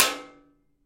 托盘升降机
描述：托盘搬运车的声音升高和降低负载。非常接近的观点。
标签： 托盘插孔 金属 工业
声道立体声